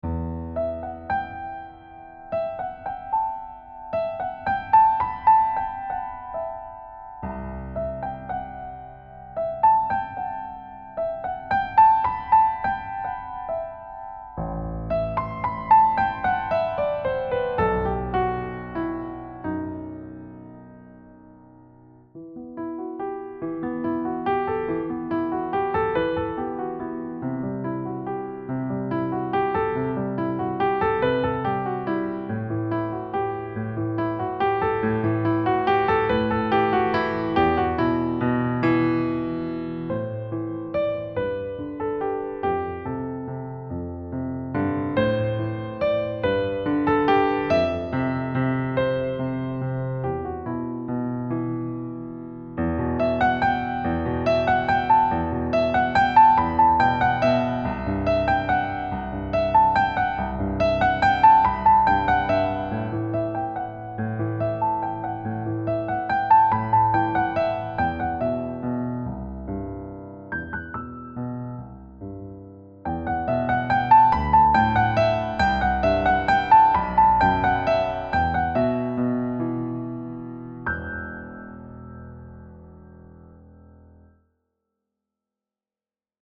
Key: E natural minor with occasional accidentals
Time Signature: 3/4
Level: Early Intermediate